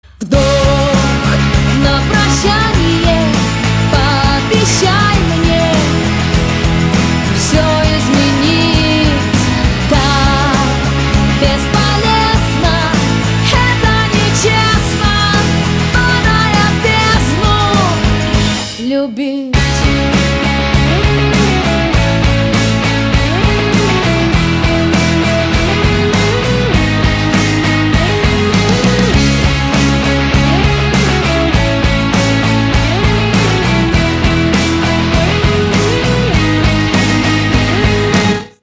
• Качество: 320, Stereo
женский вокал
Alternative Rock
Pop Rock
поп-рок